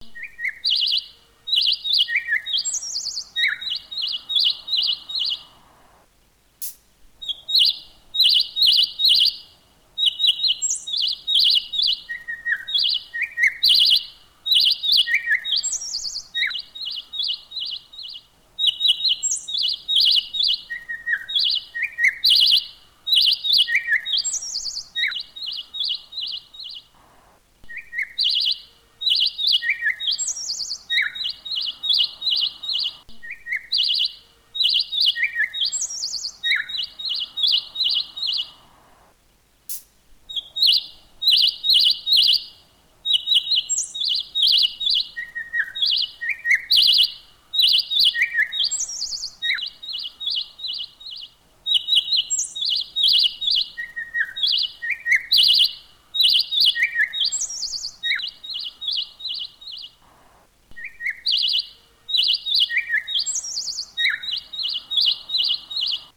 Sonneries » Sons - Effets Sonores » bruitage oiseaux matin